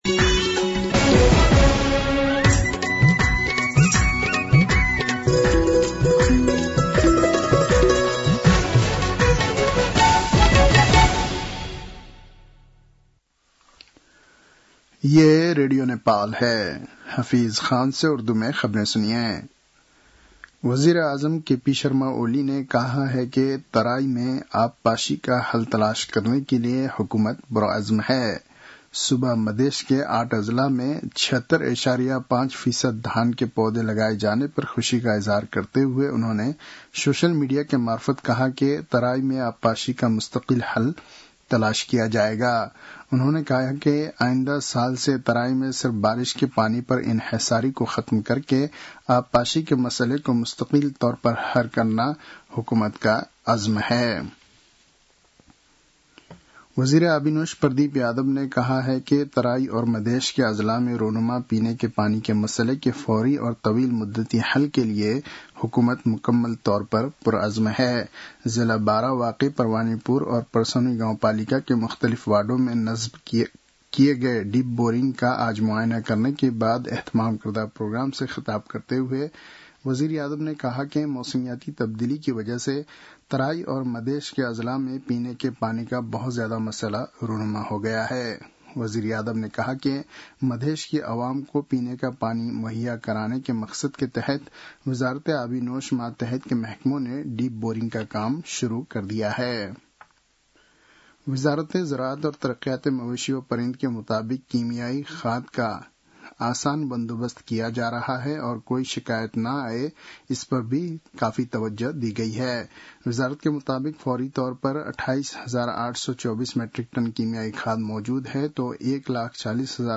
उर्दु भाषामा समाचार : २५ साउन , २०८२